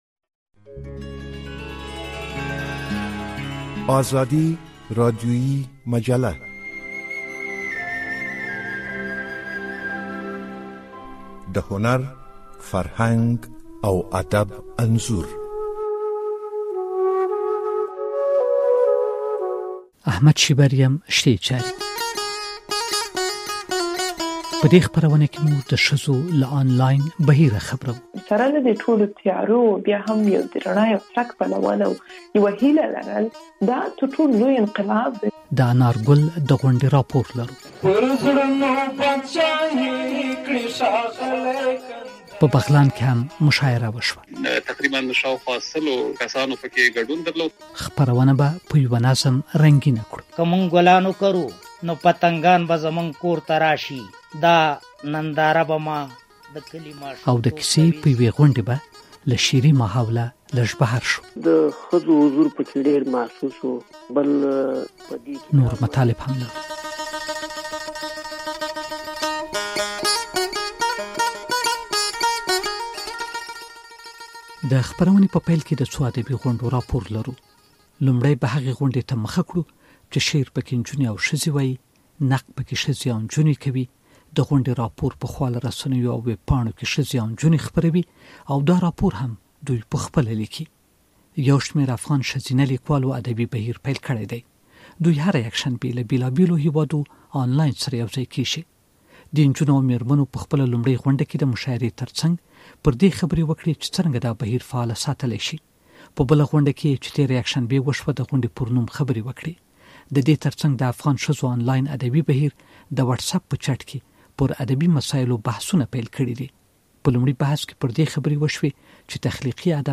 د ازادي راډيويي مجلې په دې پروګرام کې د افغان مېرمنو د ادبي بهير د غونډو حال درکول کېږي. په خپرونه کې له کندهار او بغلانه هم د ادبي غونډو او مشاعرو په اړه مالومات را اخيستل شوي دي. دغه راز د خپرونې په دوام به نظم واورئ او له دې به هم خبر شئ چې لندن کې د کيسو د يو کتاب مخکتنه وشوه.
ازادي راډیويي مجله